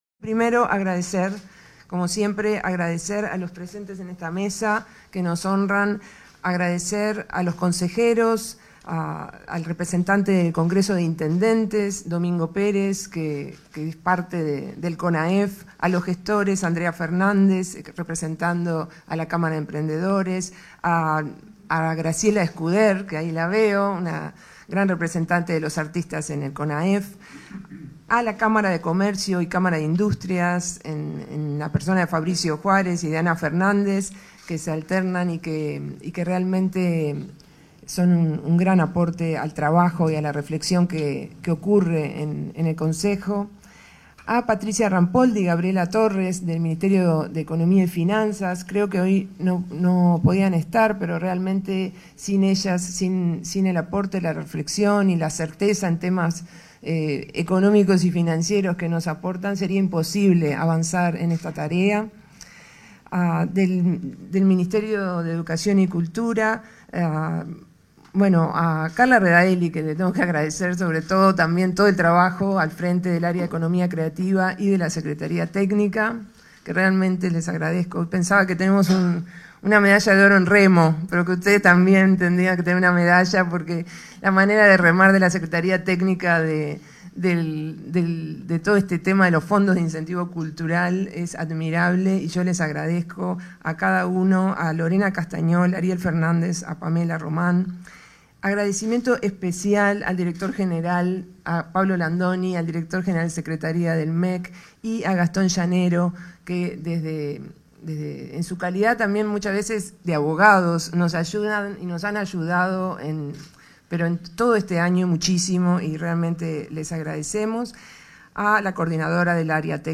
Conferencia de prensa por lanzamiento de Fondos de Incentivo Cultural
Conferencia de prensa por lanzamiento de Fondos de Incentivo Cultural 03/08/2022 Compartir Facebook X Copiar enlace WhatsApp LinkedIn Este 3 de agosto, el Ministerio de Educación y Cultura realizó la presentación de los Fondos de Incentivo Cultural. En la oportunidad, participaron el ministro de Educación y Cultura, Pablo da Silveira, y la directora nacional de Cultura, Mariana Wainstein.